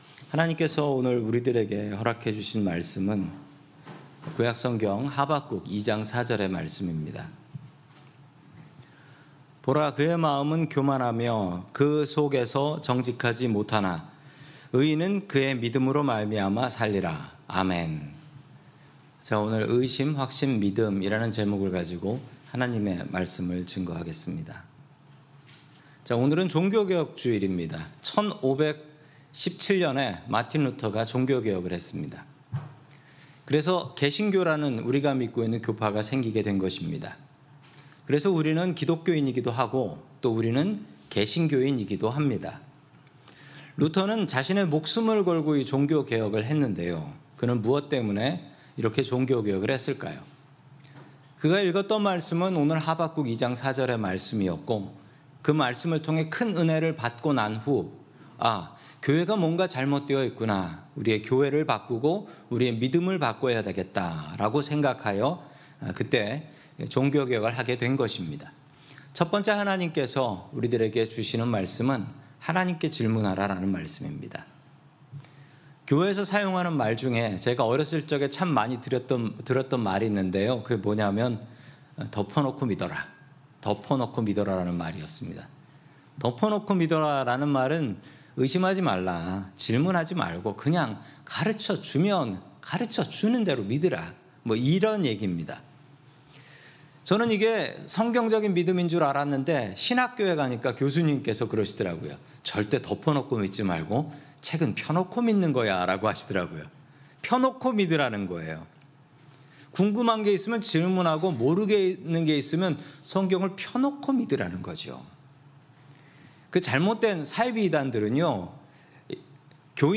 샌프란시스코 은혜장로교회 설교방송